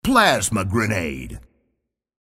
Plasma Grenade audio from the Halo: Reach Clicktacular YouTube Masthead.
HR_-_Marketing_-_Clicktacular_-_Plasma_Grenade.mp3